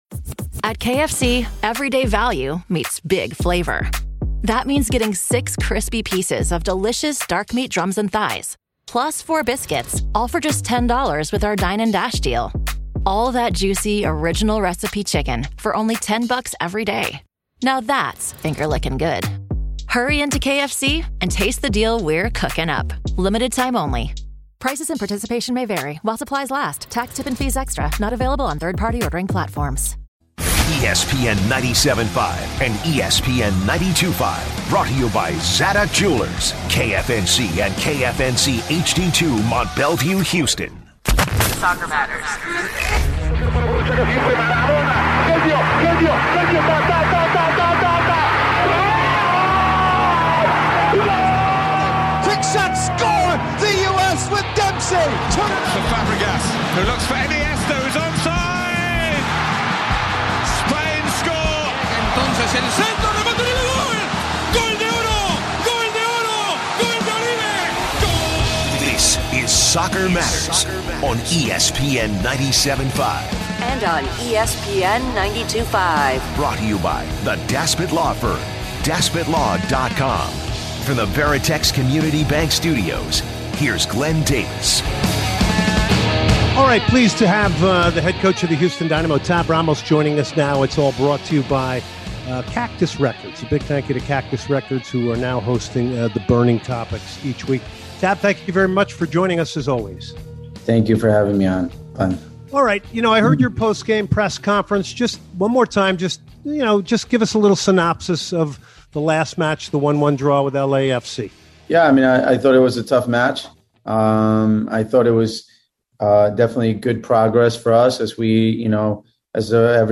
interview with former Canadian soccer player